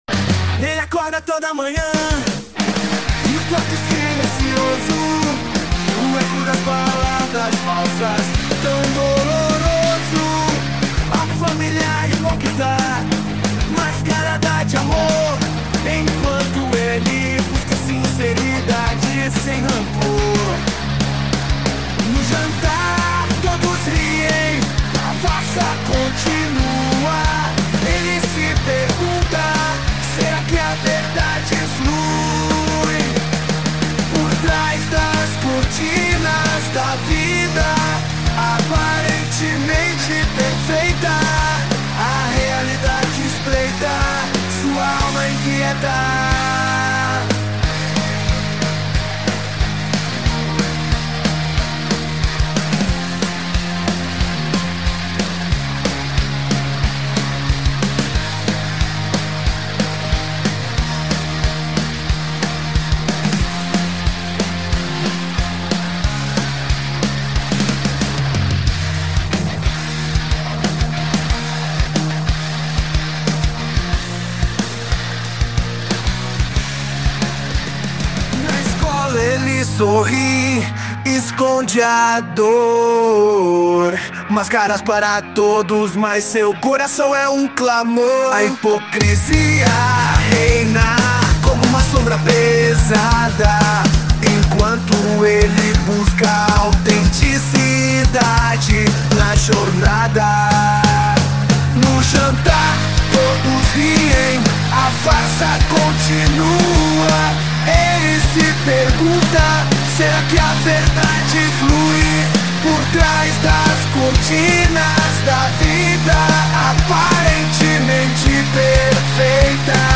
Gênero Funk.